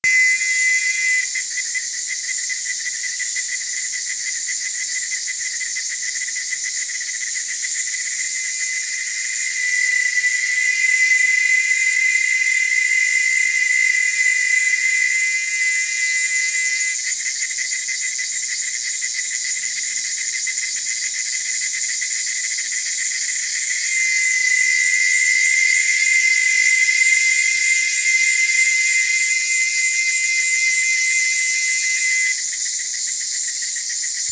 Cigale
D’ailleurs, c’est la plus grande RAISON de ma FASCINATION : leurs chants très différents et oh! SI FORTS!!!!
Je partage avec vous, pour que vous puissiez aussi avoir accès à ce phénomène exceptionnel, 2 extraits sonores de ce que nous entendons toute la journée!